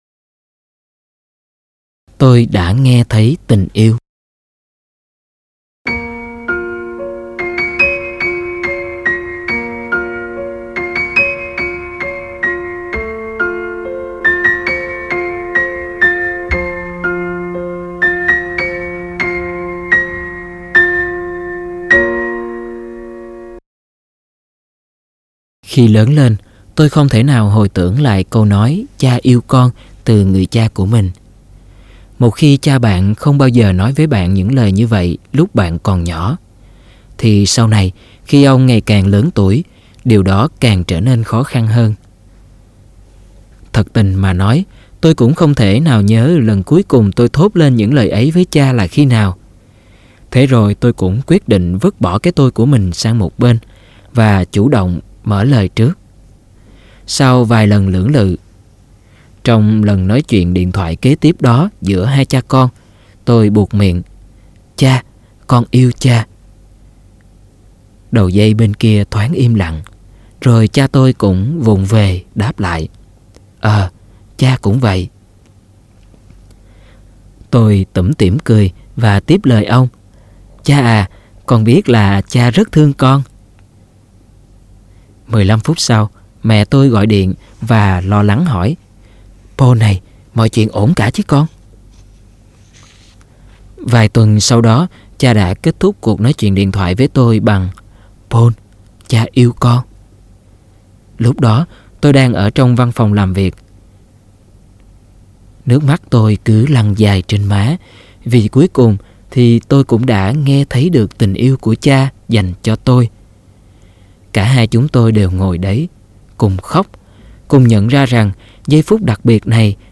Sách nói Chicken Soup 3 - Chia Sẻ Tâm Hồn Và Quà Tặng Cuộc Sống - Jack Canfield - Sách Nói Online Hay